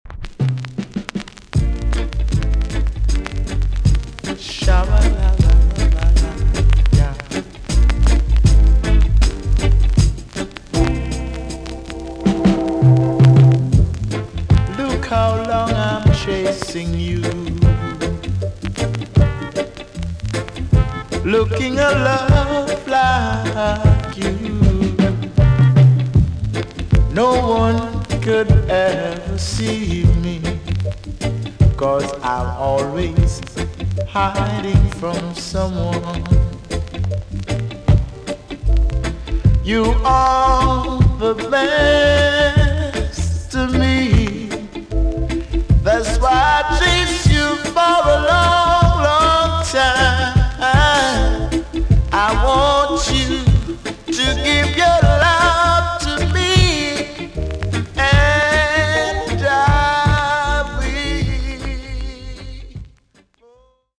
TOP >80'S 90'S DANCEHALL
VG+ 少し軽いチリノイズが入ります。